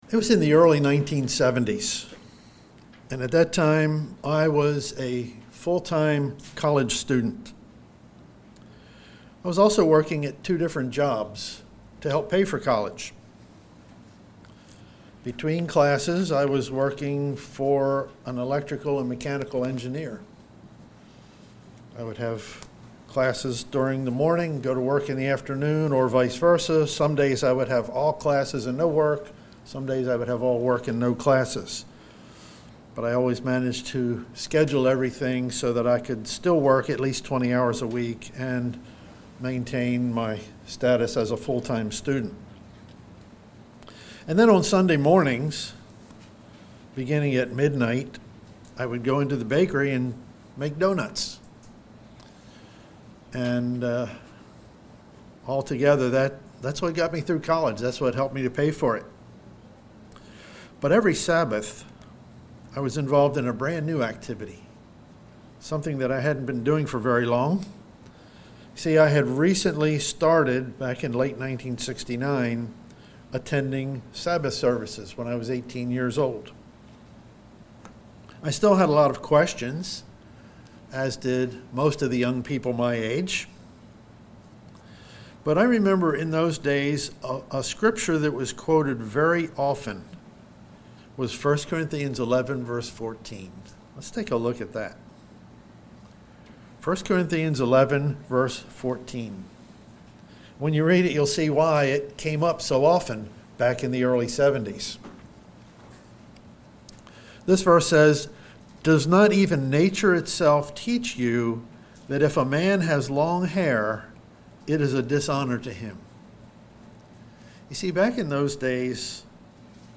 Sermons
Given in Delmarva, DE